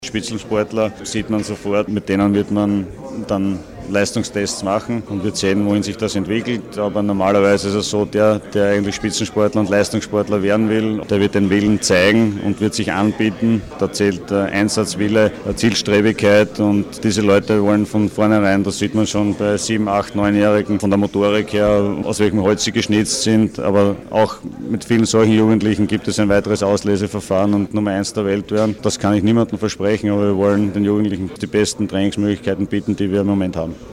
Statements
Fragen an Thomas Muster: